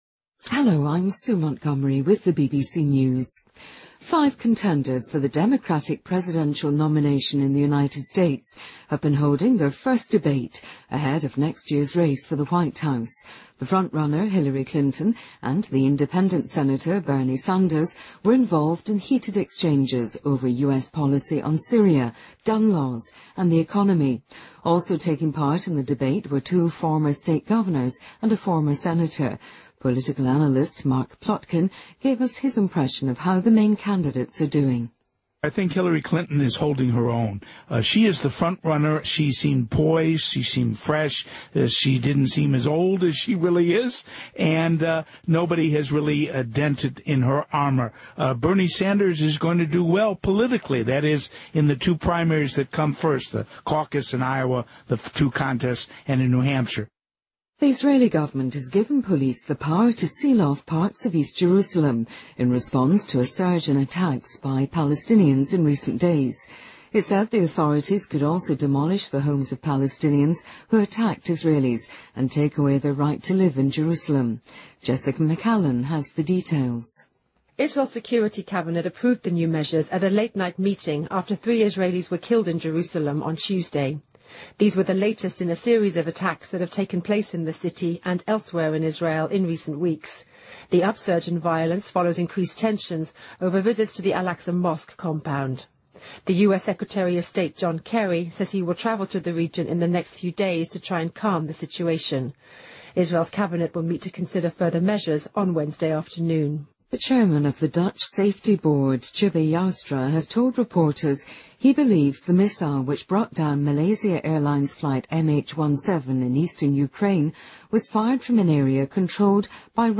BBC news,美国民主党总统初选首场辩论开始